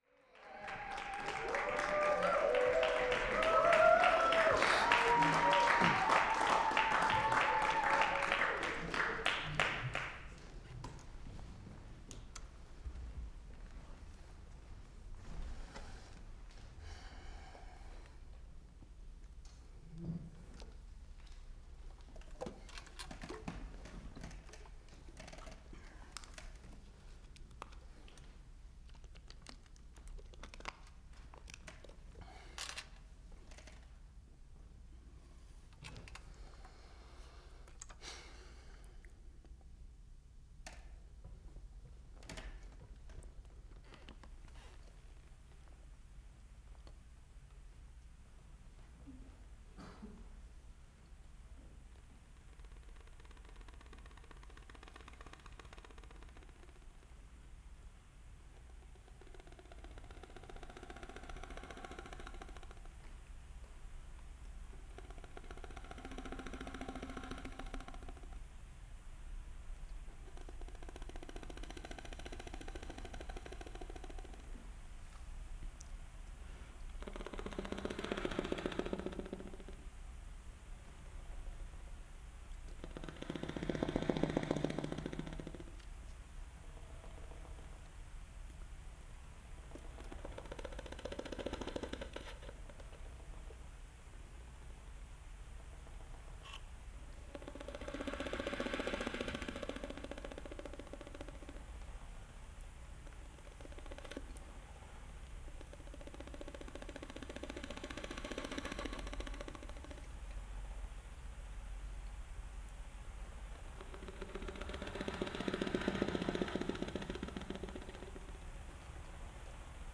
new solo music for saxophone and modular synthesizers
saxophone
Describing his approach as “surreal ASMR
constructs a piece around the quiet extreme of his saxophone